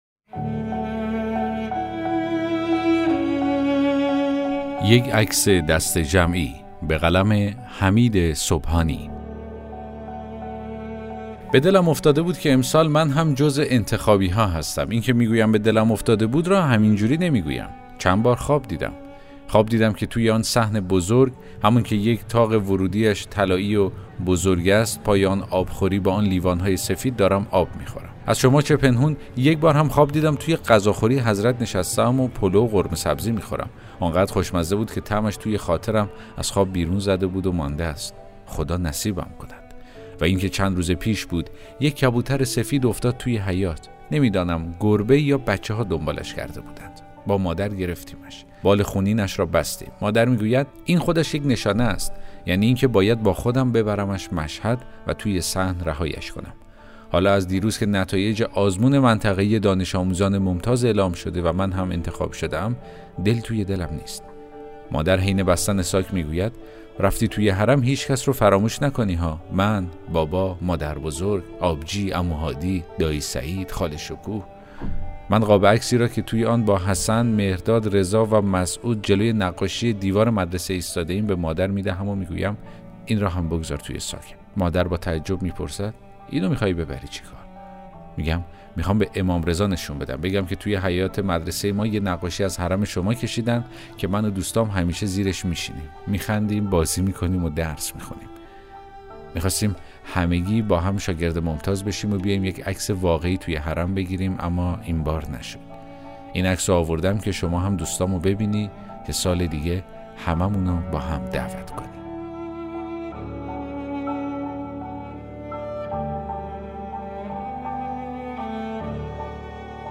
داستان صوتی: یک عکس دسته جمعی